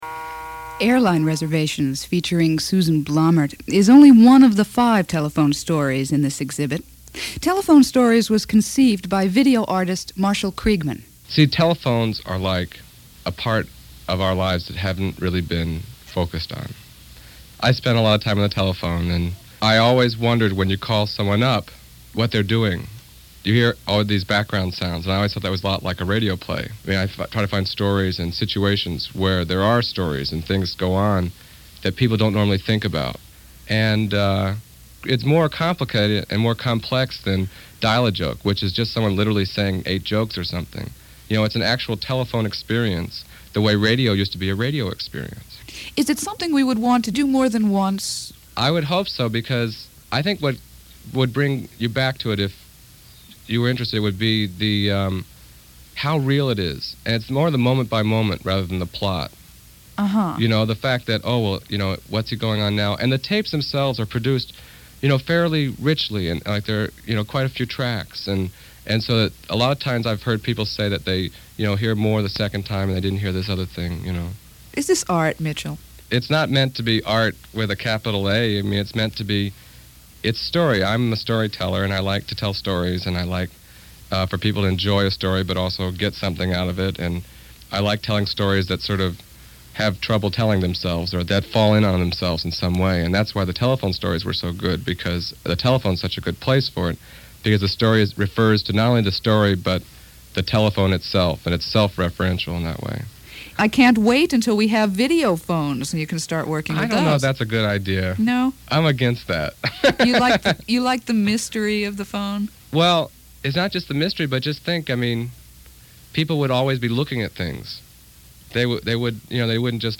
The two Telephone Stories below are excerpted from a 1980 National Public Radio interview in connection with the exhibition.